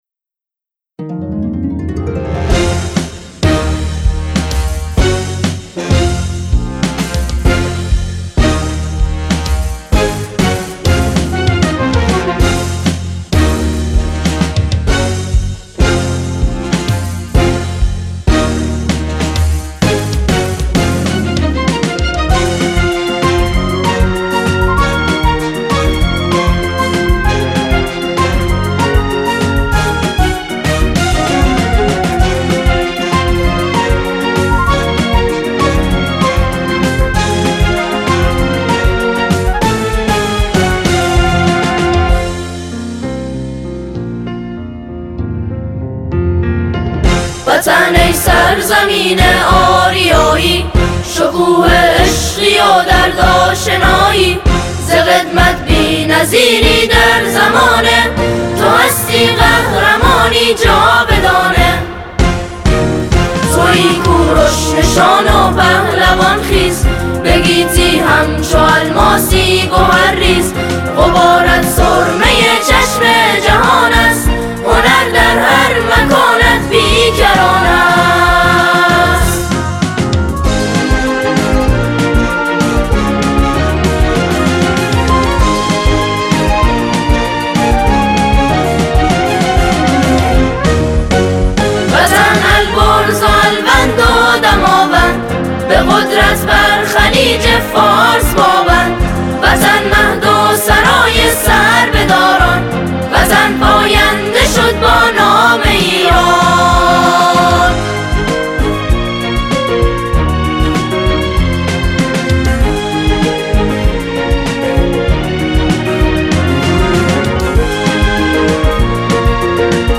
نماهنگ میهنی
ژانر: سرود